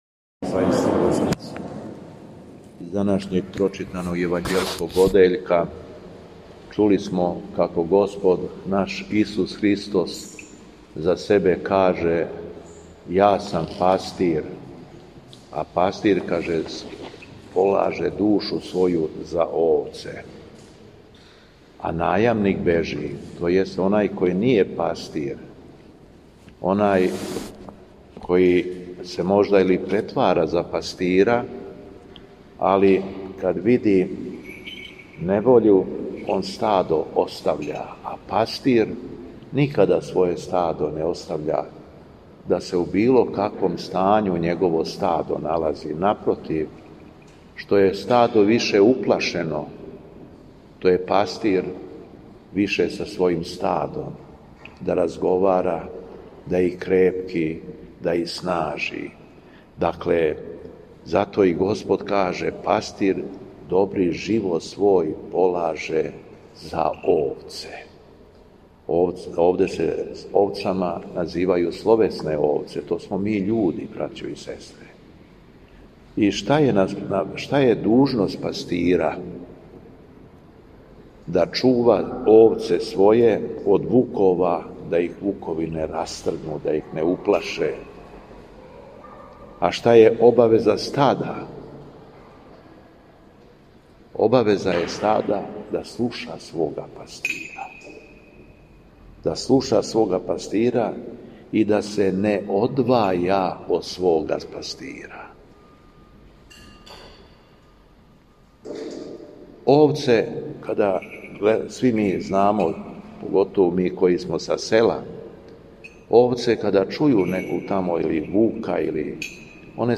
У понедељак пети по Васкрсу, када наша света Црква прославља светог праведног и многострадалног Јова и пренос моштију светога оца нашега Саве, Његово Високопреосвештенство Митрополит шумадијски Господин Јован, служио је свету архијерејску литургију у храму Светога Саве у крагујевачком насељу Аеродро...
Беседа Његовог Високопреосвештенства Митрополита шумадијског г. Јована